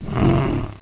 GROWL5.mp3